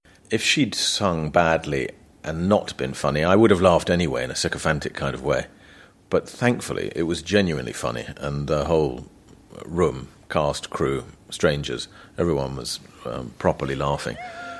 And here are the three types of [a] sound together.
/sʌŋ/ /ˈbædli/ /ˈfʌni/ /lɑːft/ /ˌsɪkəˈfæntɪk/ /ˈθæŋkfəli/ /ˈfʌni/ /kɑːst/ /ˈlɑːfɪŋ/